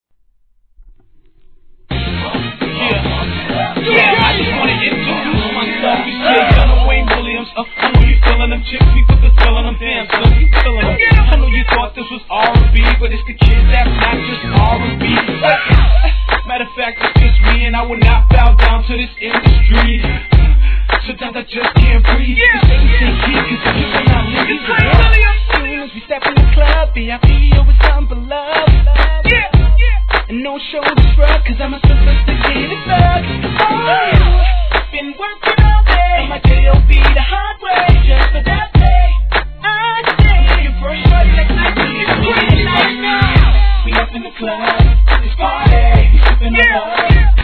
HIP HOP/R&B
HIP HOPクラシック・サンプリングを多用したタイトル通りのPARTY ANTHEM!!